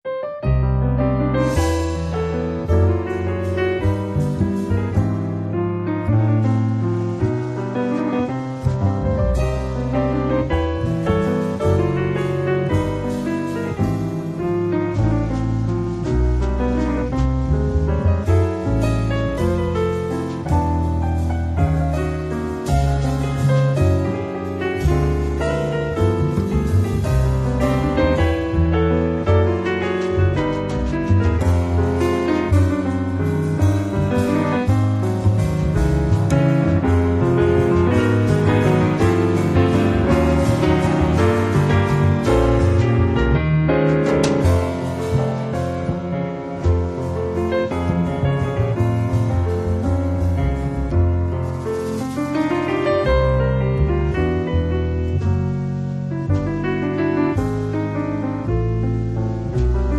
piano
basso
batteria